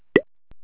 button.au